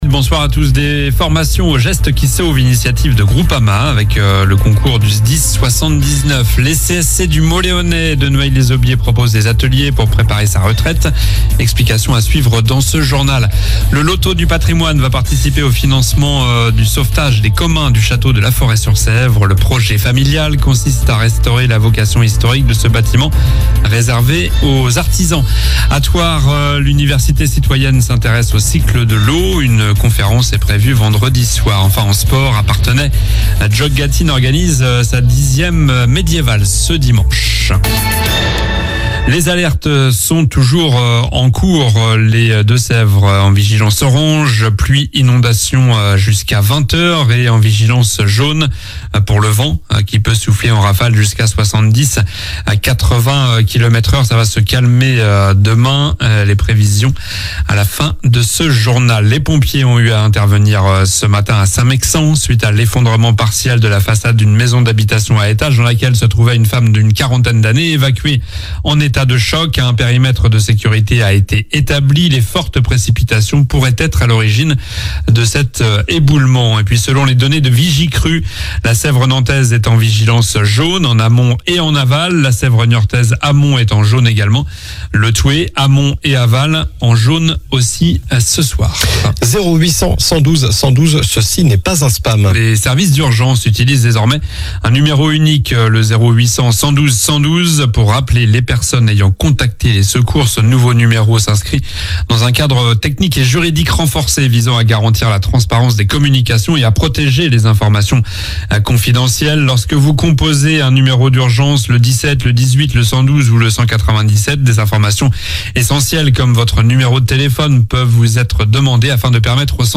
Journal du mercredi 09 octobre (soir)